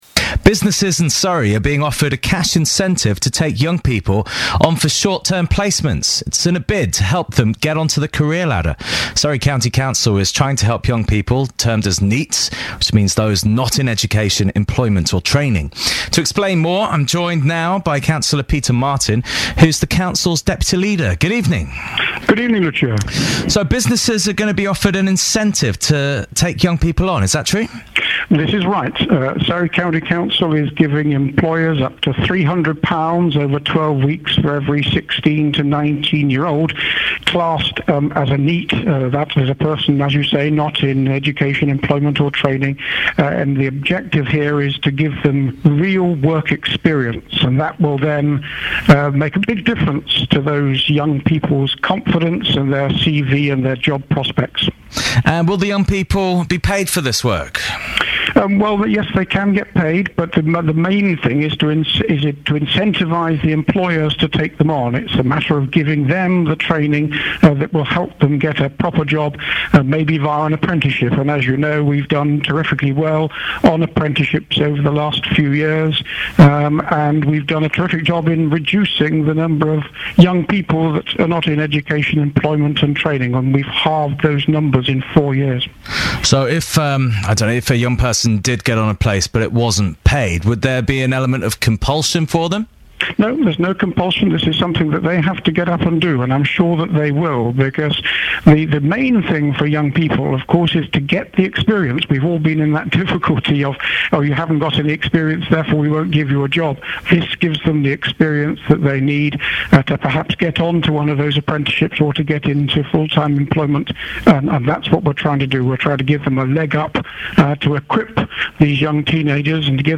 BBC interview on cash incentives for firms offering work experience